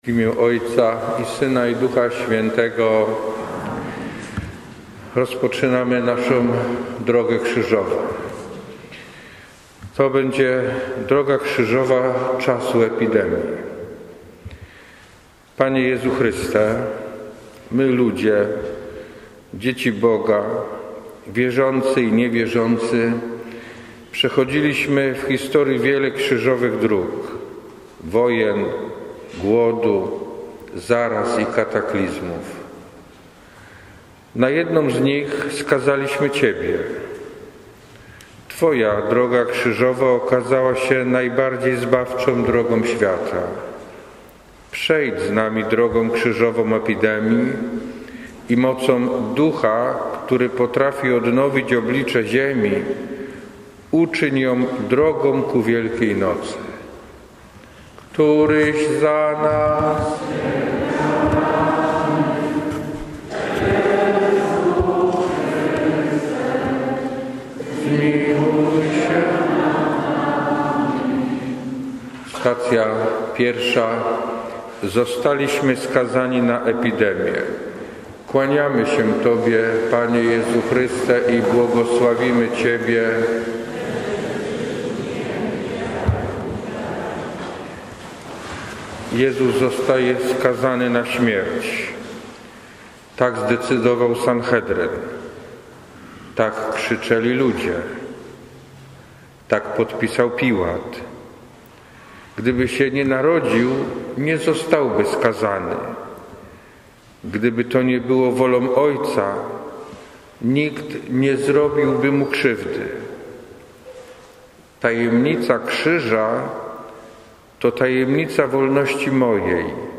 ROZWAŻANIA DROGI KRZYŻOWEJ – Parafia Bożego Ciała